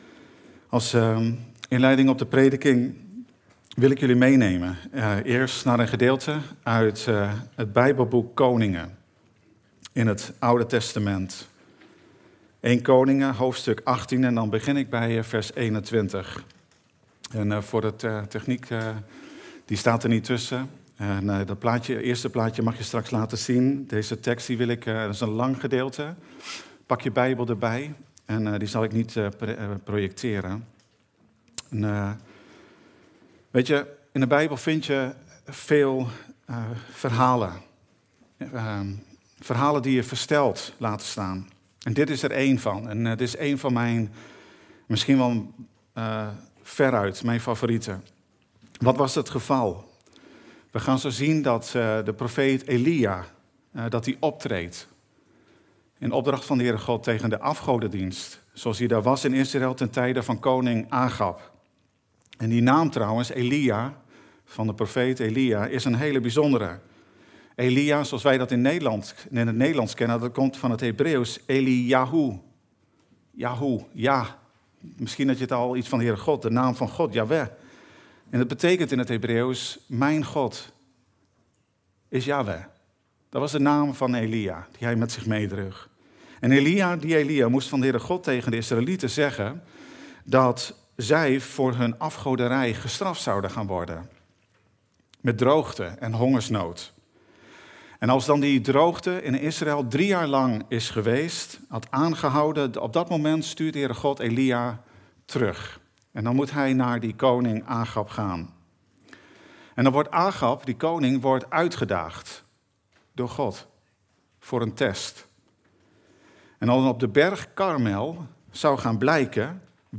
De vreze des HEEREN Dienstsoort: Eredienst « God spreekt tot ons door zijn schepping